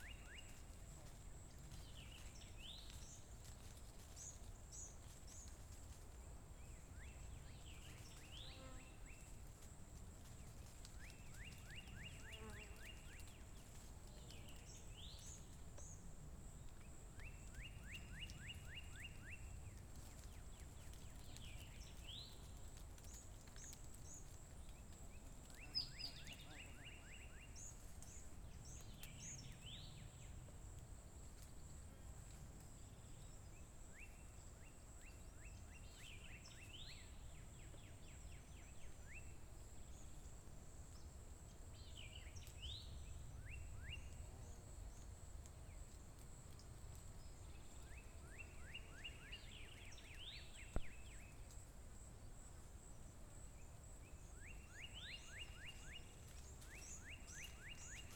Jungle-Sounds.ogg